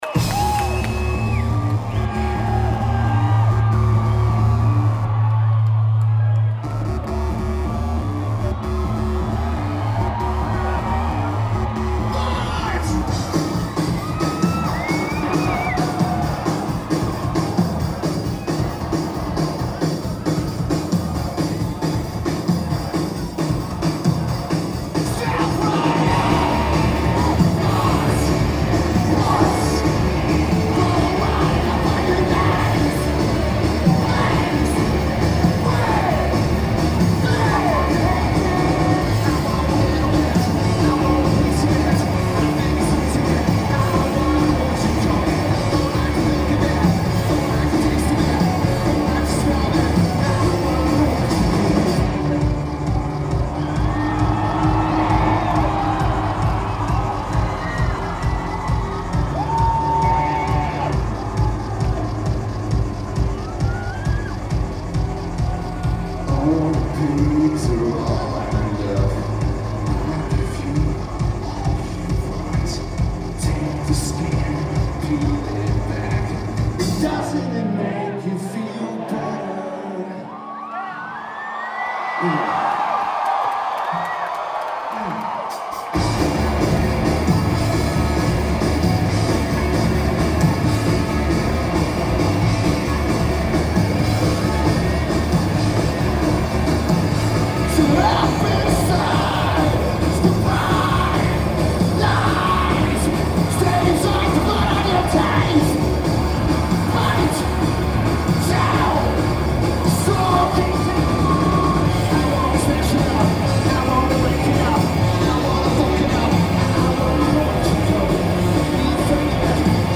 The Warfield
Lineage: Audio - AUD (CSB's + Sharp MT161)